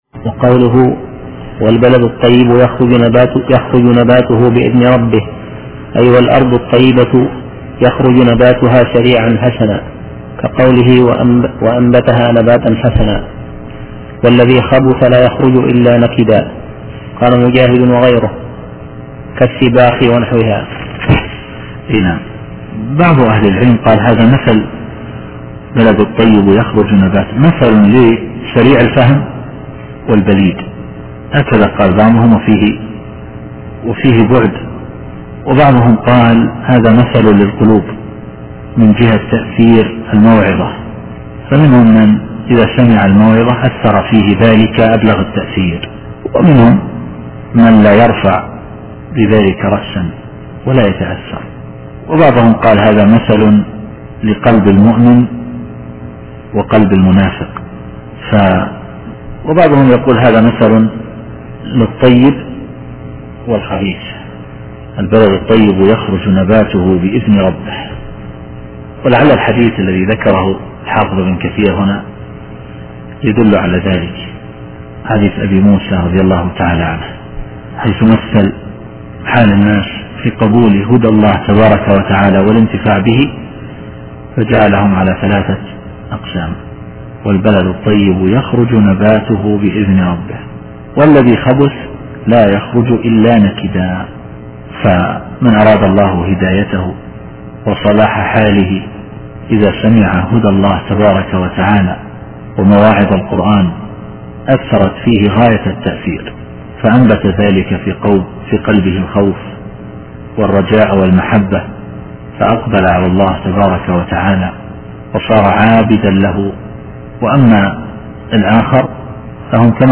التفسير الصوتي [الأعراف / 58]